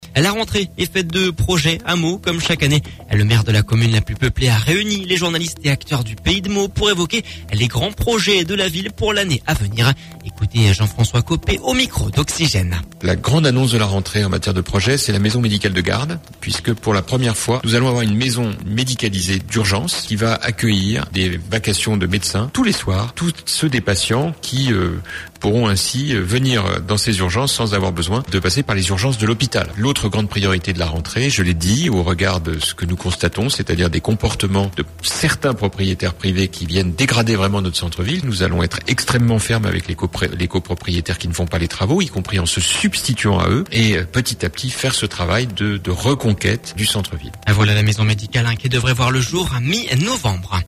Comme chaque année, le maire de la commune la plus peuplée a réuni les journalistes et acteurs du Pays de Meaux pour évoquer les Grands Projets de la ville pour l’année à venir. Jean-François Copé au micro Oxygène… La maison médicale devrait voir le jour mi-novembre !